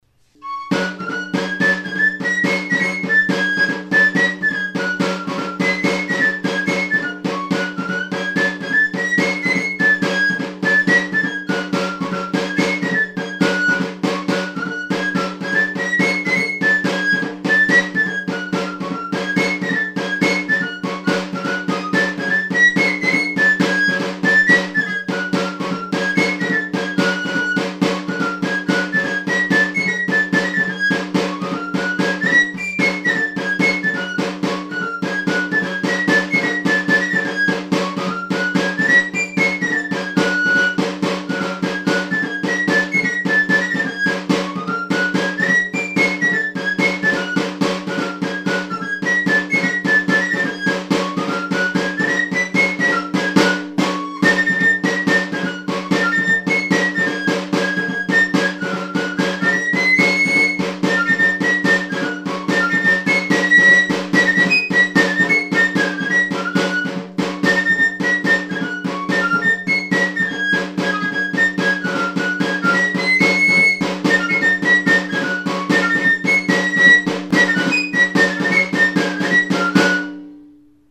Aerophones -> Flutes -> Fipple flutes (one-handed)
Recorded with this music instrument.
Hiru zuloko flauta zuzena da. Fa# tonuan dago.